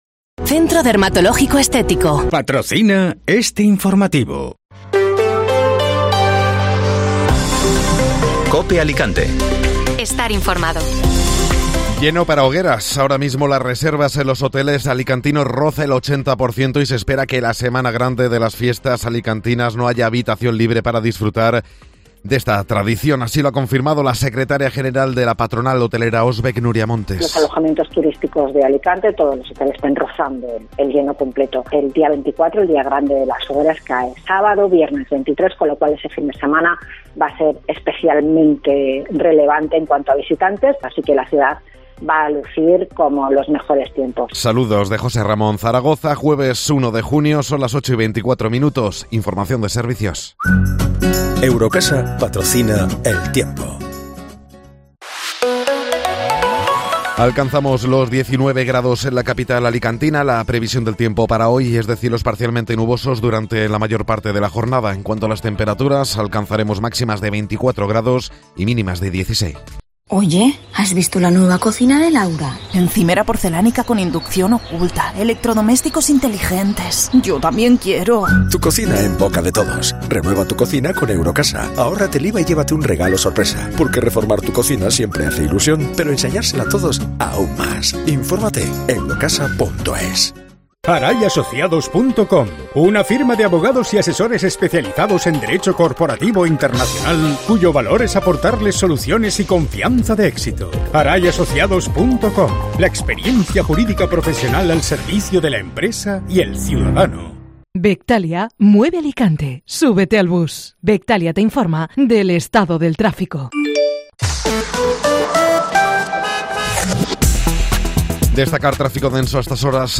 Informativo Matinal (Jueves 1 de Junio)